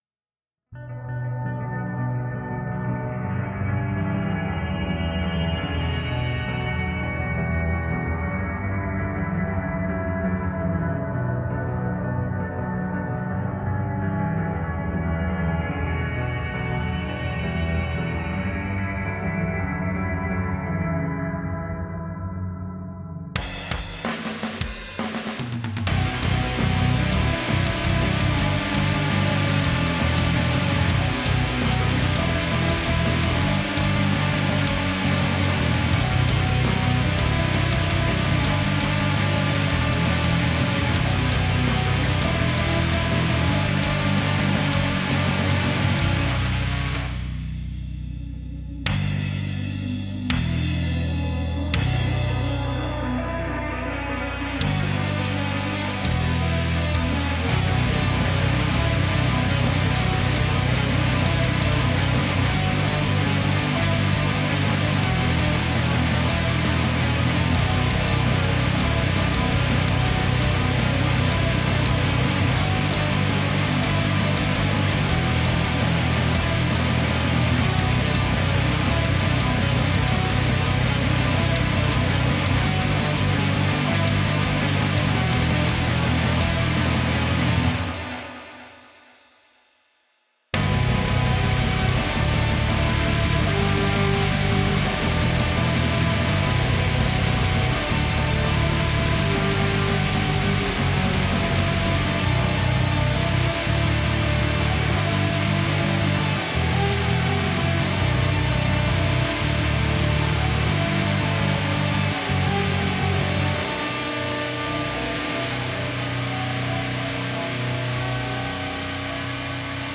Another silly demo.
Also, this is my first work I created using real instrument - I played all the guitar tracks by myself (drums and bass are still VSTs). It's more like a bunch of rough sketches in one file than a complete song, though.
Music / Rock
music rock metal guitar drums piano bass demo vst daw moody heavy